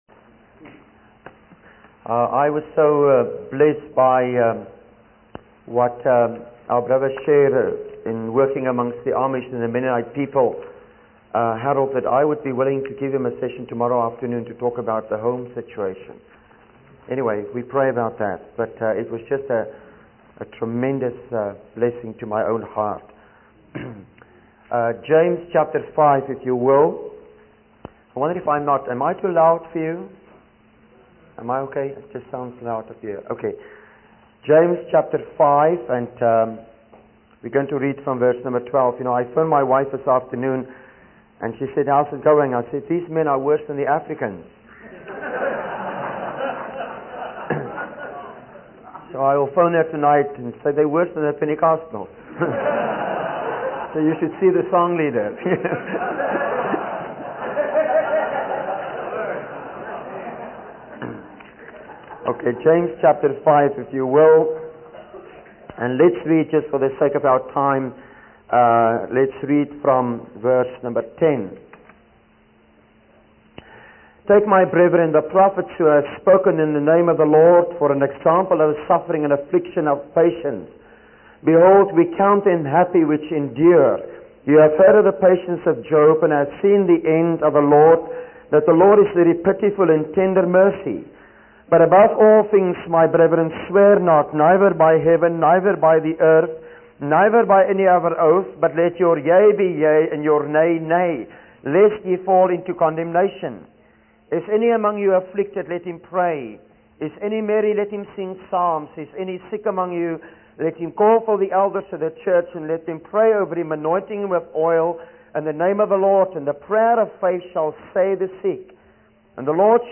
In this sermon, the speaker shares his experience of evangelism in Africa and Scotland, where they would spend several weeks before making any invitations to allow people to be convicted by the Word of God. He emphasizes that the Holy Spirit works through the communication of God's Word to fertilize and manifest a life of the Word in ordinary people.